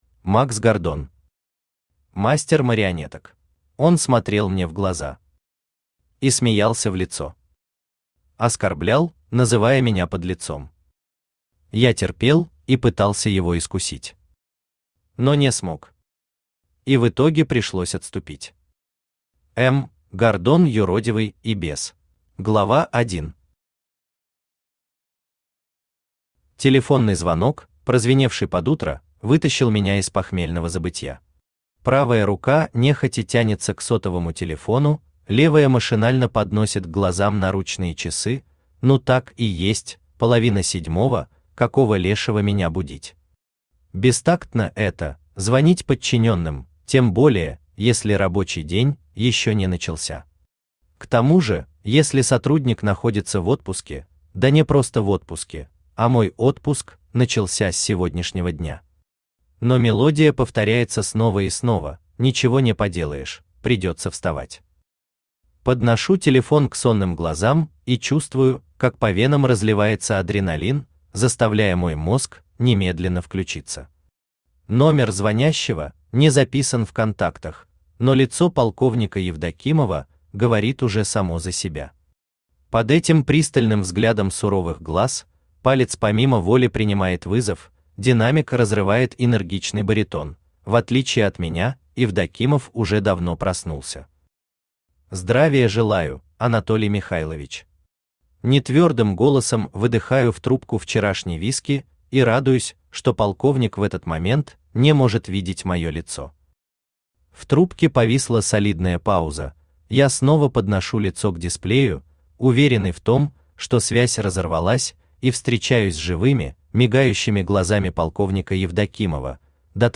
Читает: Авточтец ЛитРес
Аудиокнига «Мастер марионеток».